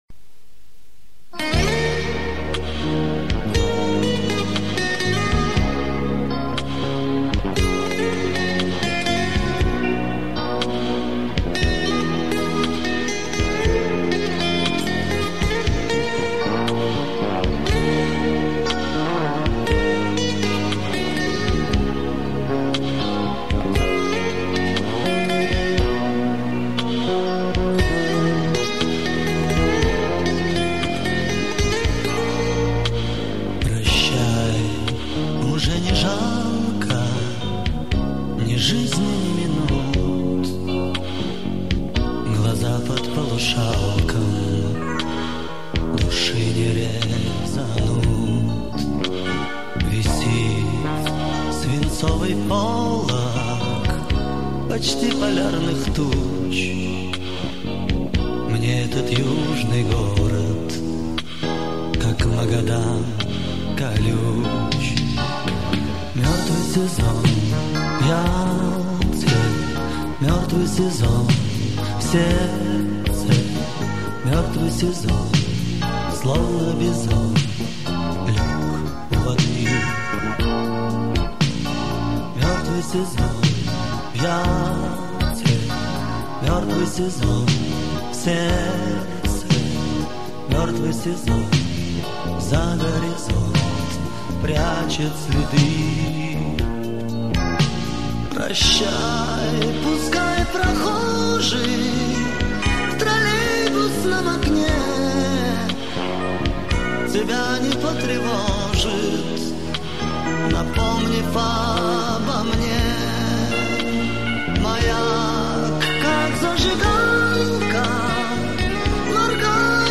Предлагаю вашему вниманию музыкальные заставки придумывал и записывал сам на магнитоле "Panasonic CT980". Оцифровка с кассет.